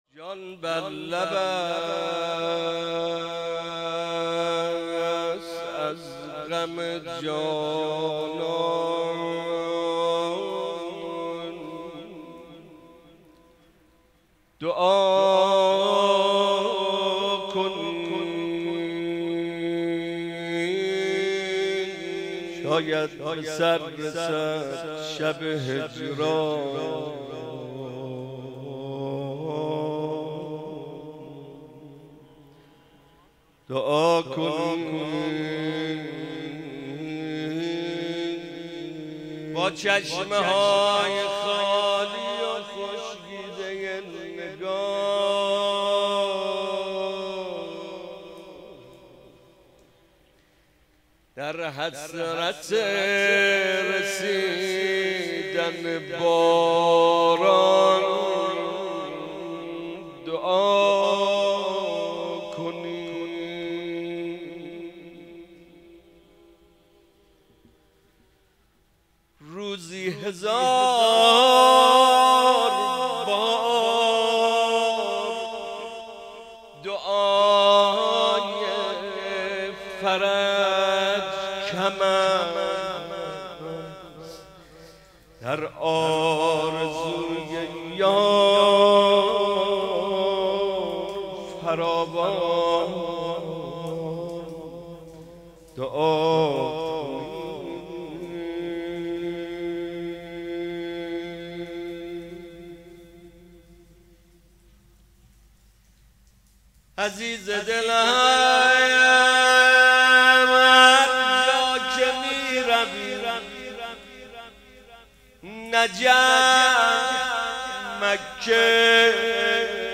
روضه امام رضا محمد رضا طاهری | پلان 3